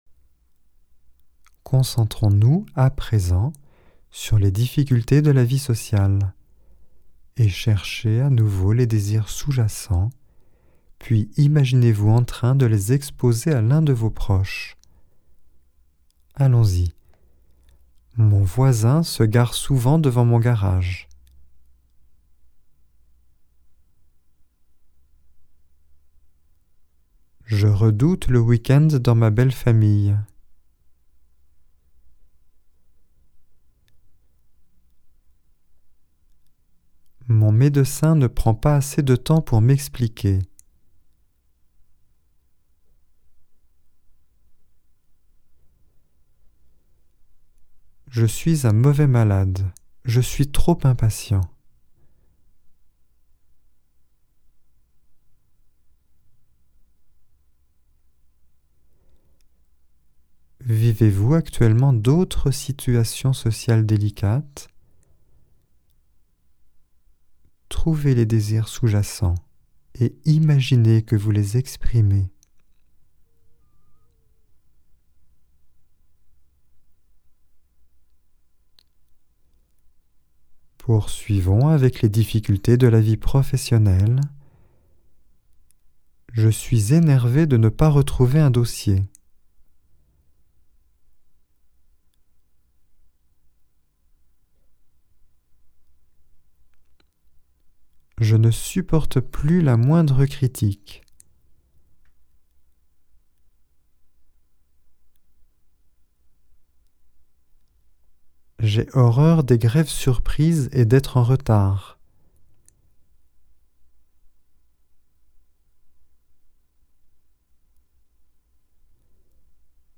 Genre : Meditative.